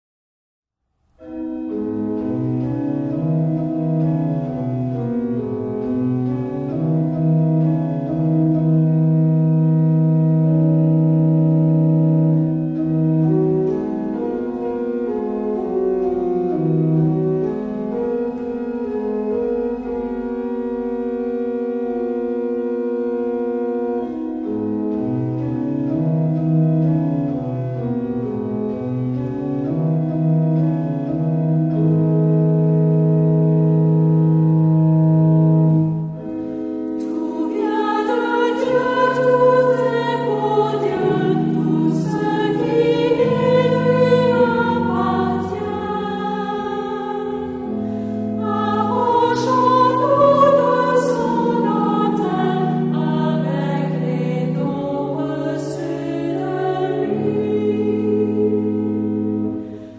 Genre-Style-Forme : Sacré ; Offertoire
Caractère de la pièce : calme
Type de choeur : unisson
Instruments : Orgue (1)
Tonalité : la mineur